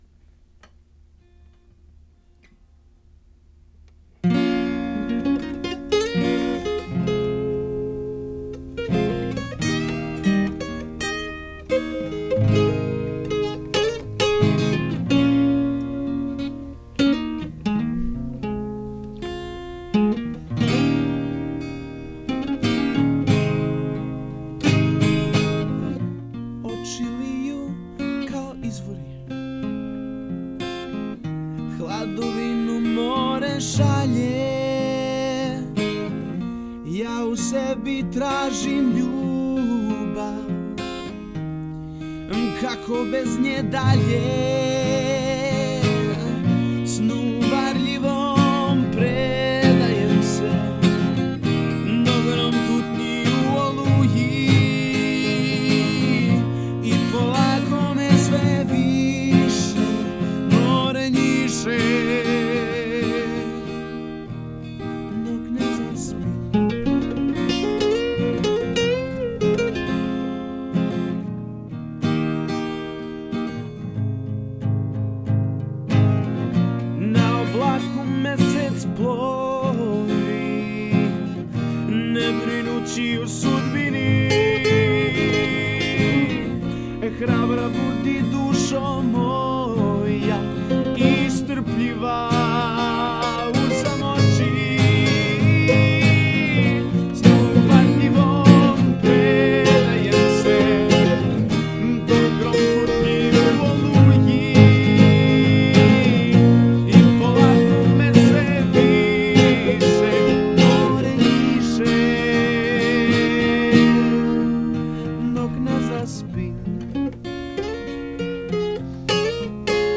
One might even say that I rarely pick up my acoustic guitar these days.
Musically, it is pretty simple. Starting and ending with four chords, the only thing that I needed to add before the song gained depth was a little bit of solo guitar.